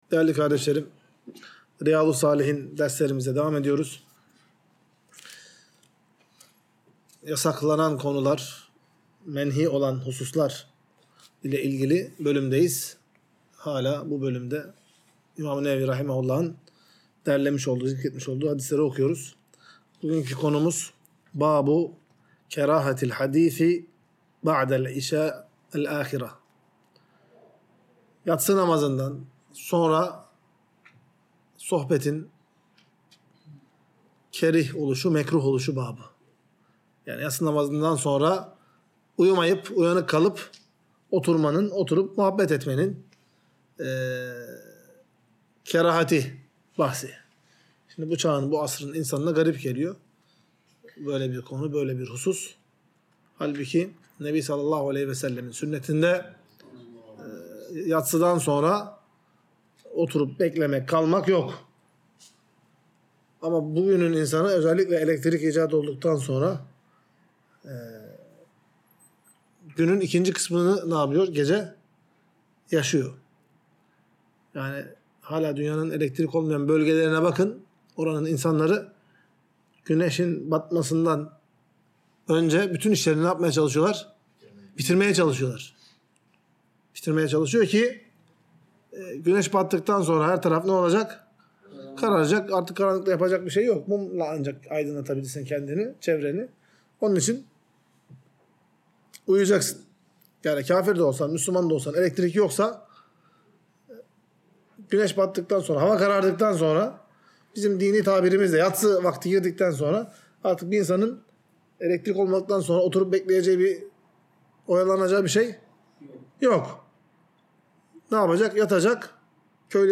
Ders - 81. Bölüm | Yatsıdan Sonra Konuşmanın Mekruh Olduğu